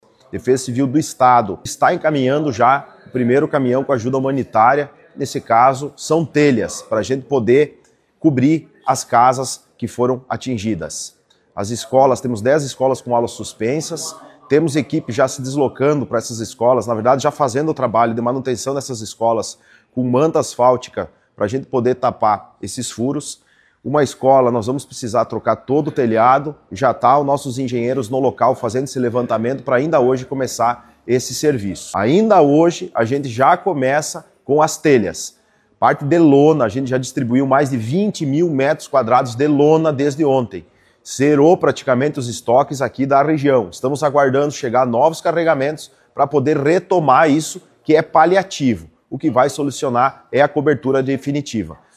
Pablo Mari antecipa que várias residências sofreram perda total dos telhados. Ainda frisa que a Defesa Civil estadual vai ajudar com telhas. (Abaixo, áudio do prefeito Pablo)